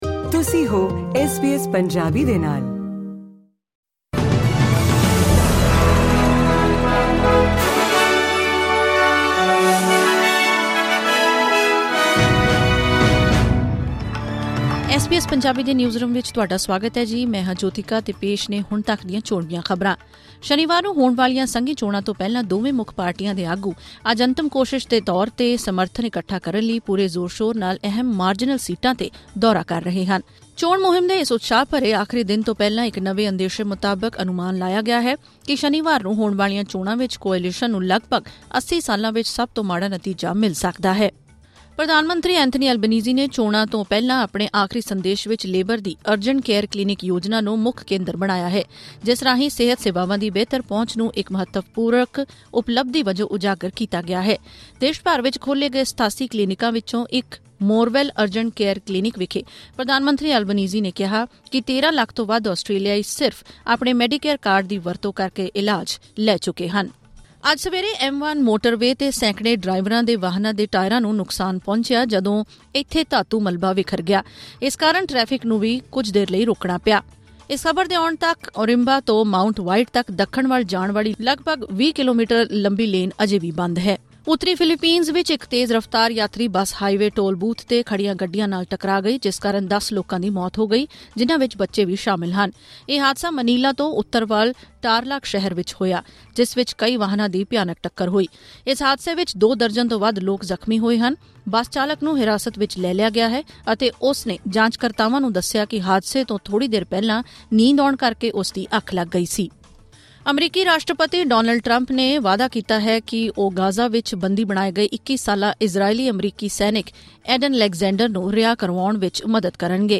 ਖਬਰਨਾਮਾ: ਆਗੂਆਂ ਵੱਲੋਂ ਸਖਤ ਸੀਟਾਂ 'ਤੇ ਆਖਰੀ ਜ਼ੋਰ, ਕੋਅਲੀਸ਼ਨ ਲਈ ਮਾੜੇ ਨਤੀਜਿਆਂ ਦੀ ਭਵਿੱਖਬਾਣੀ